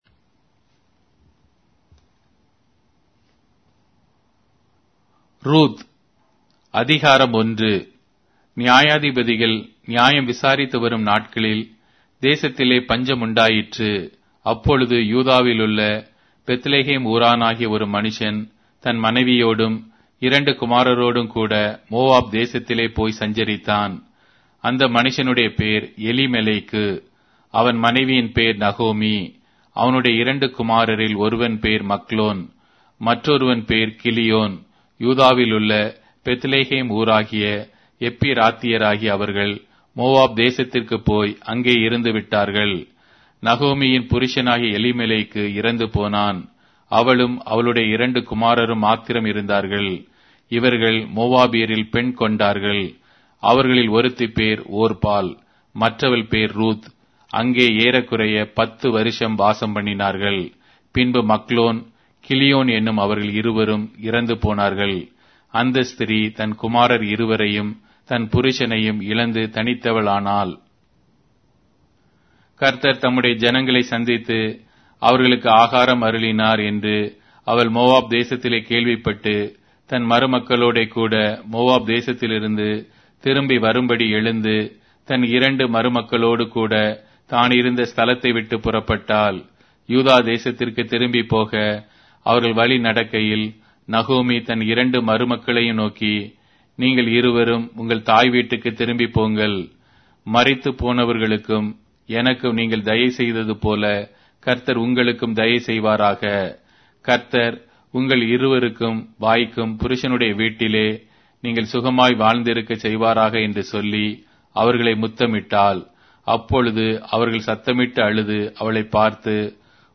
Tamil Audio Bible - Ruth 1 in Mhb bible version